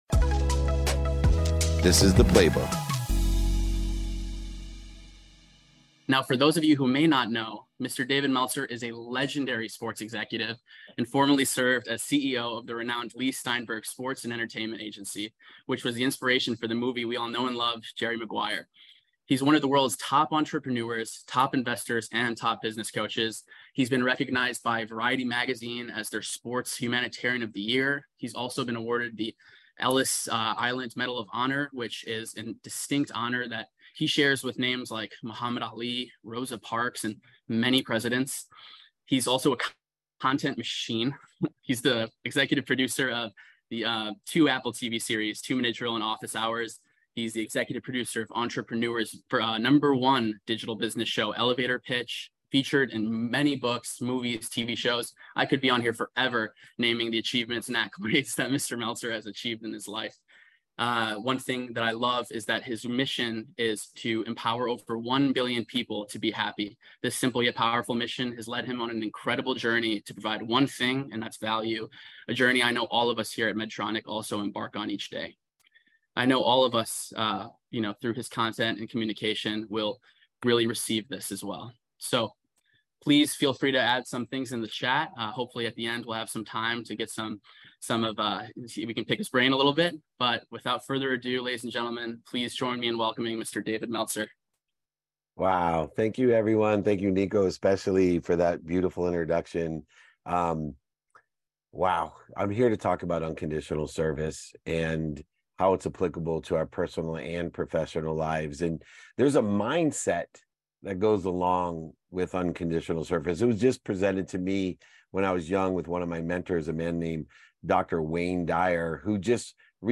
Today’s episode is from a talk I gave to the Medtronic sales team. We dive into why gratitude is more than just a buzzword and how sharing knowledge isn’t just good for others, but for you too.
Plus, we tackle the thin line between genuinely knowing your stuff and just faking it. Stick around – I answer Q&A at the end of the episode.